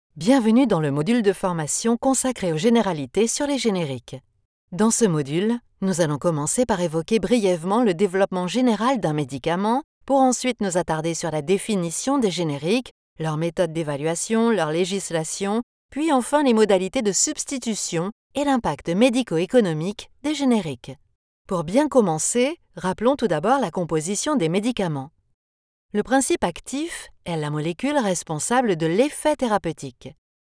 Female
French (Parisienne)
Adult (30-50)
Medical Narrations
Medical E-Learning